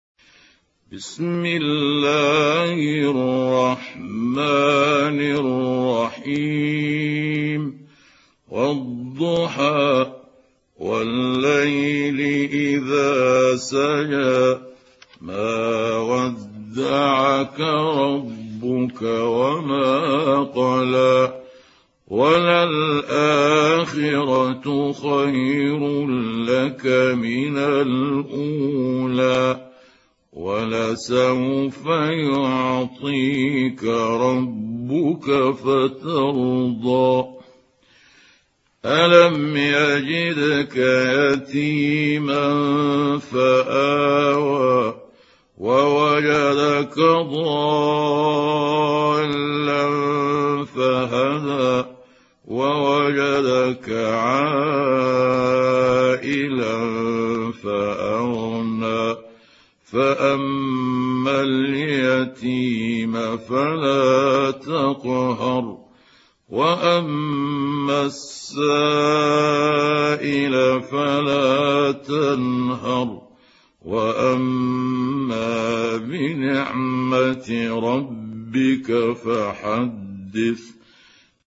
سورة الضحى | القارئ محمود عبد الحكم